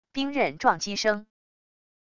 兵刃撞击声wav音频